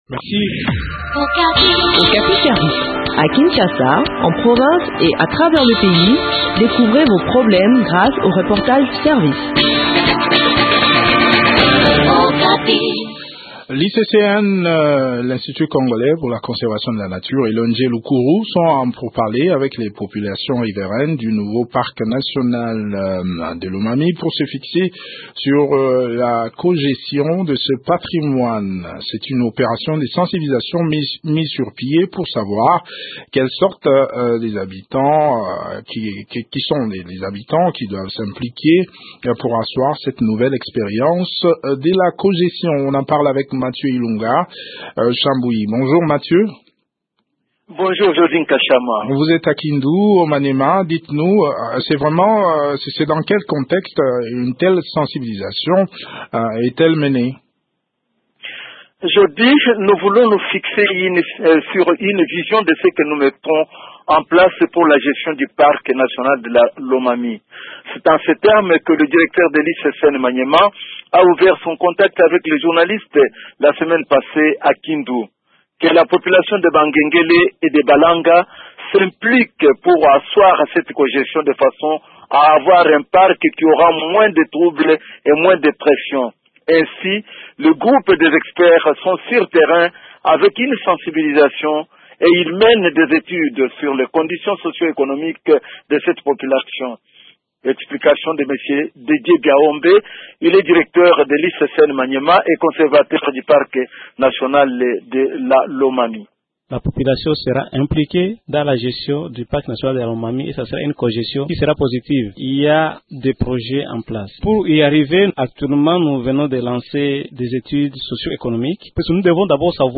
Le point sur cette campagne de sensibilisation dans cet entretien